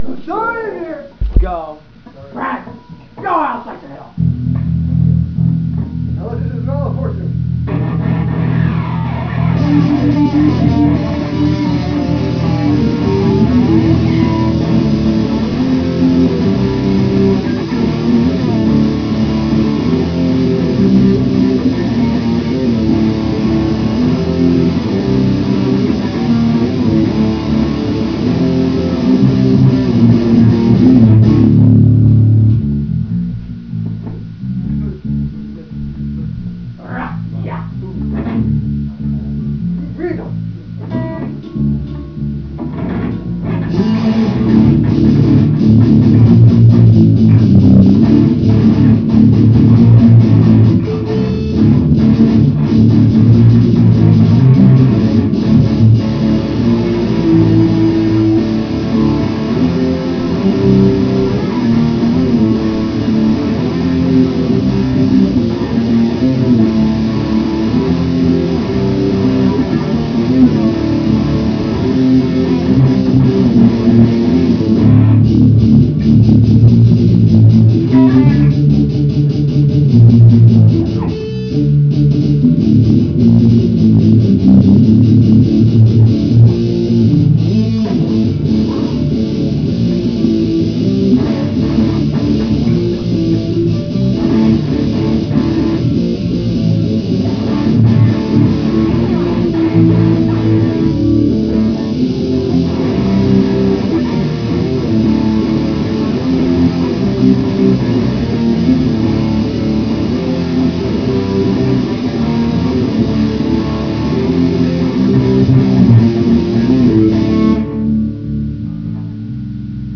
My Fire (no sing/drum. really old)